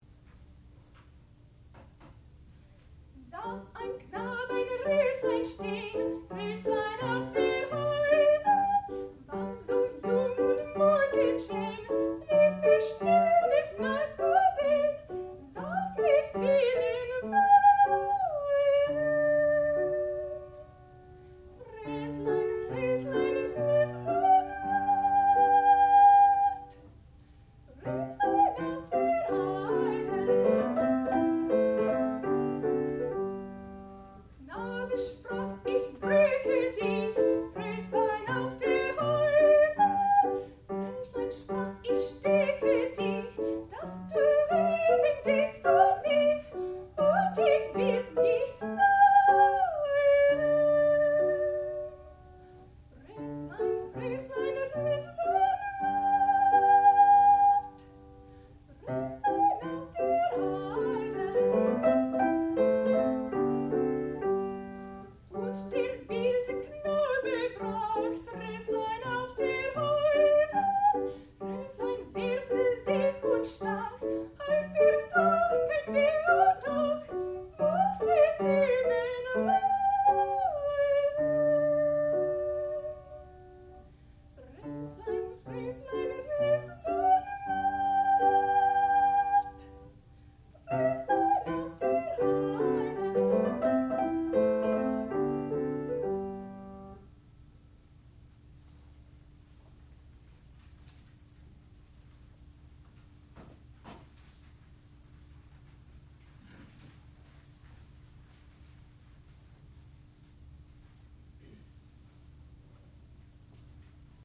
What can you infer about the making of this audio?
Student Recital